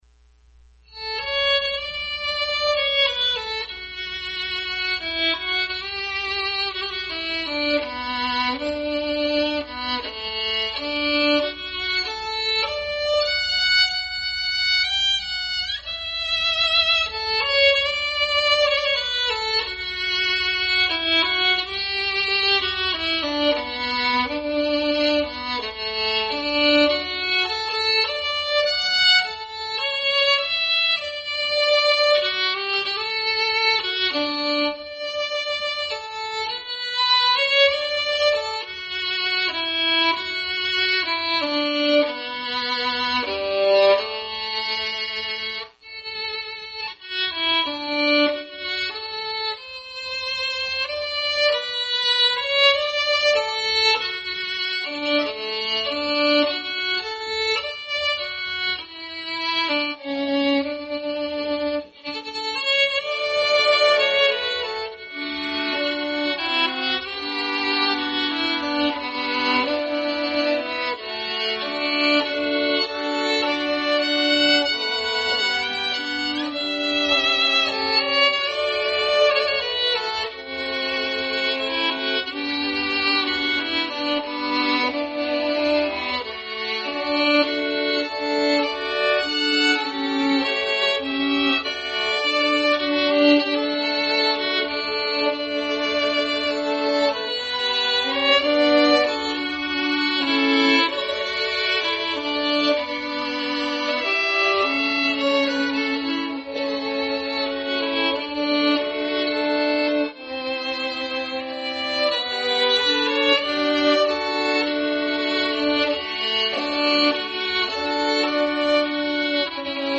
Special Music
by Violin Trio   Ashokan Farewell
played Missouri USA 8 Oct 2025
25_Oct08musicViolinTrioAshokanFarewell.mp3